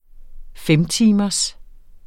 Udtale [ ˈfεmˌtiːmʌs ]